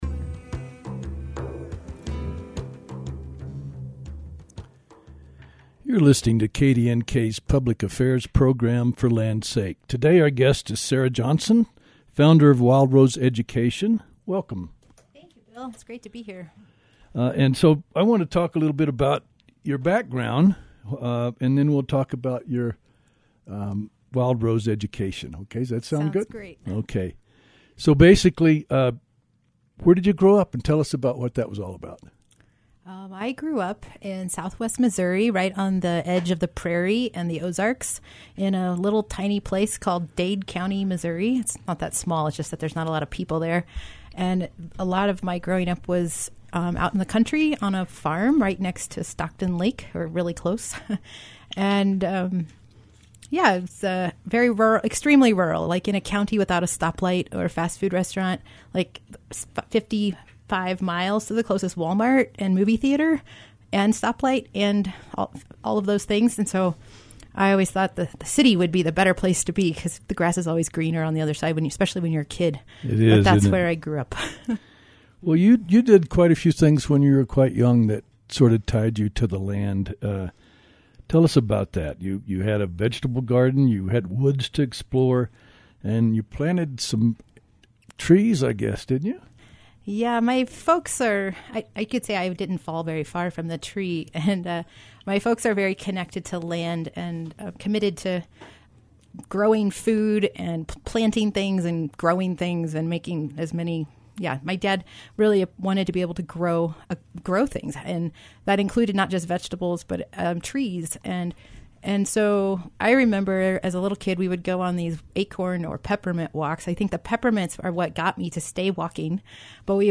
a show featuring guests sharing thoughts on their personal connection to the land.